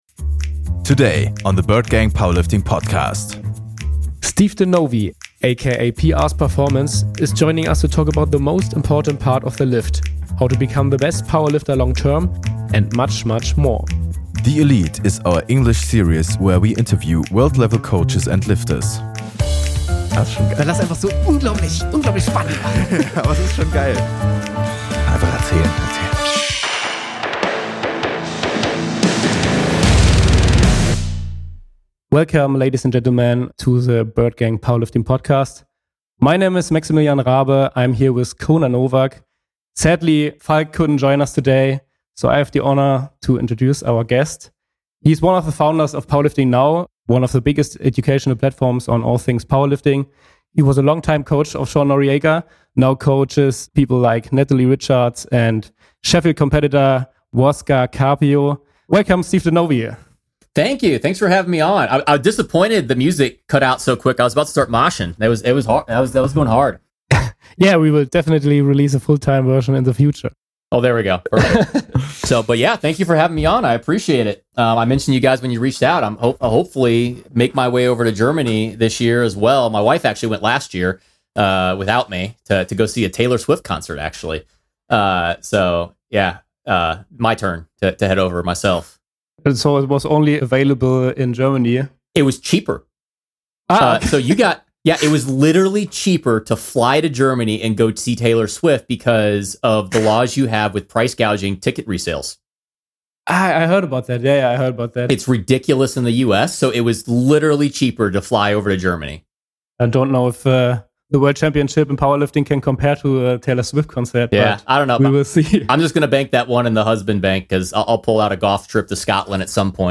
THE ELITE is our english series where we interview world level coaches and lifters.